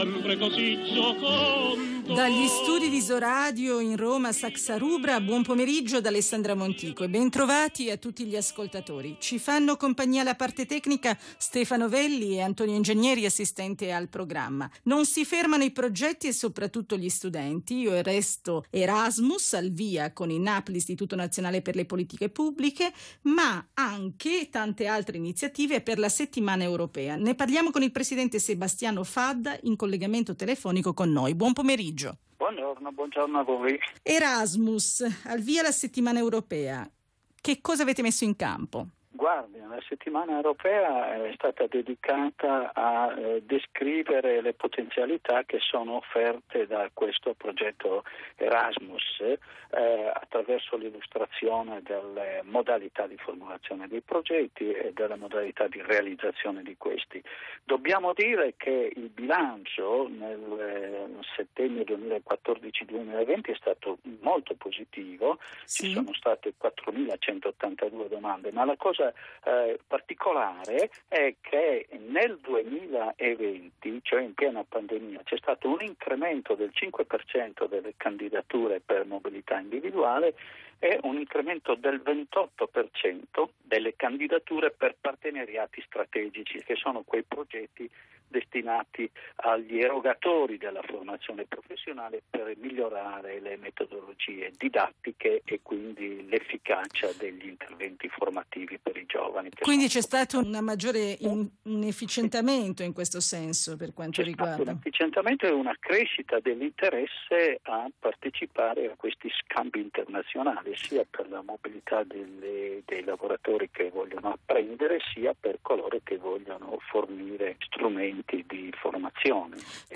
Ascolta l'intervento del Presidente in trasmissione
Il Presidente Fadda interviene alla trasmissione "Si riparte"